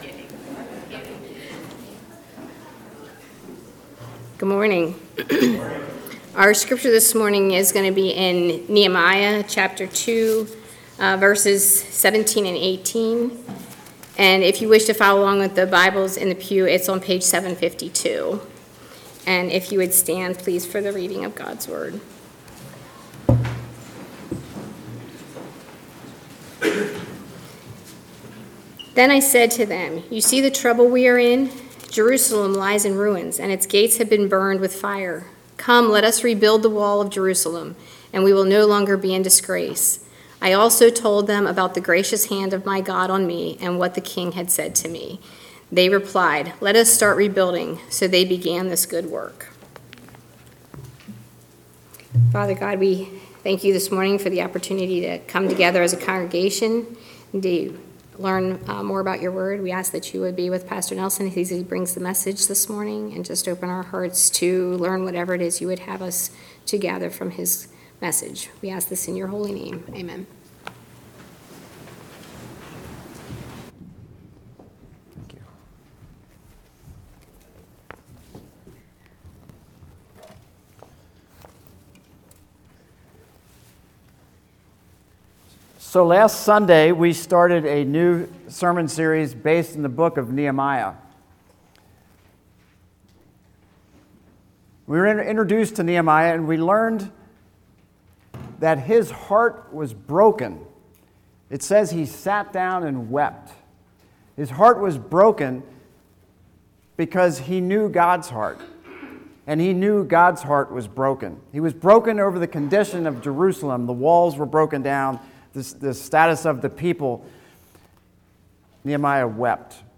A message from the series "January 2026."